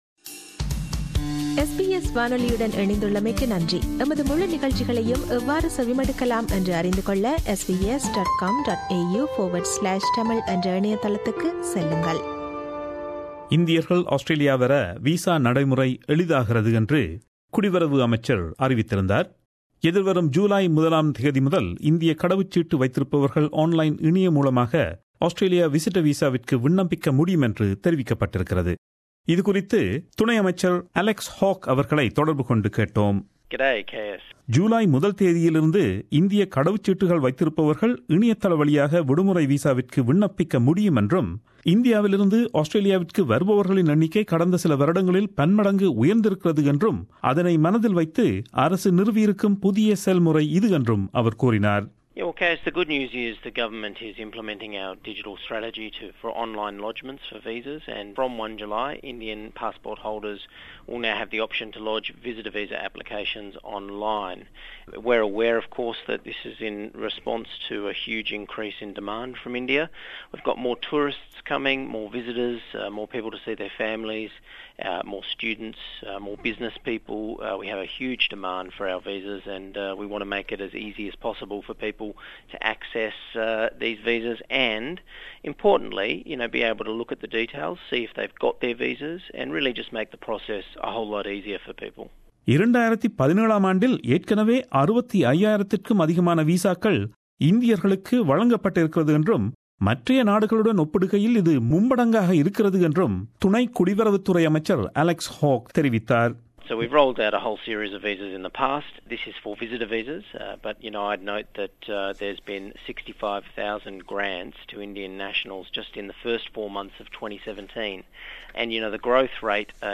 talks to the Assistant Minister for Immigration and Border Protection, Alex Hawke, to find out more.